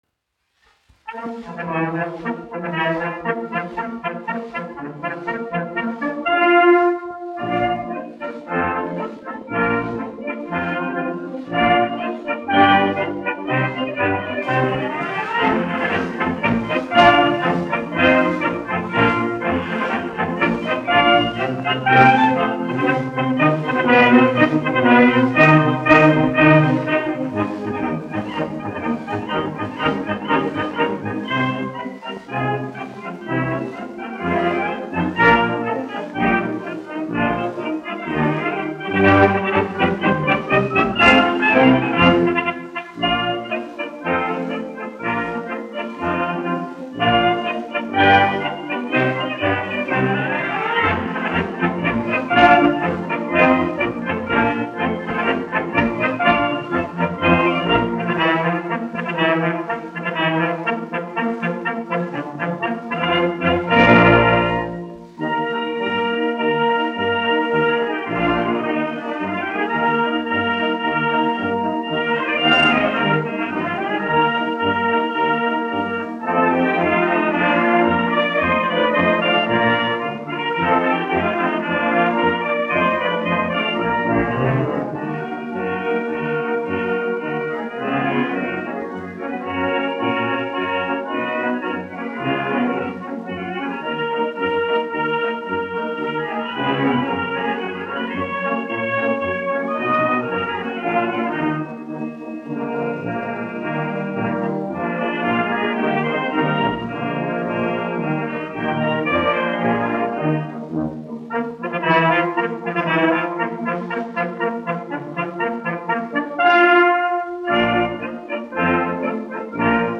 1 skpl. : analogs, 78 apgr/min, mono ; 25 cm
Marši
Pūtēju orķestra mūzika, aranžējumi
Skaņuplate
Latvijas vēsturiskie šellaka skaņuplašu ieraksti (Kolekcija)